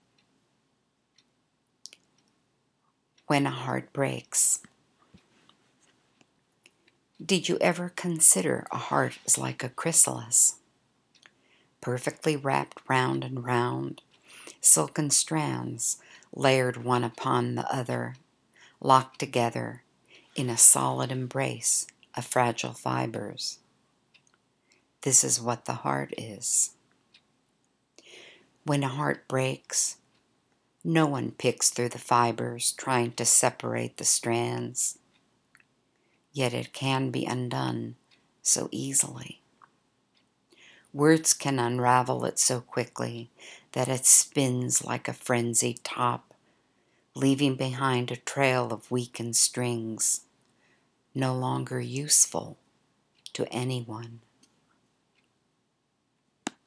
An audio of me reading this poem is available at the link below.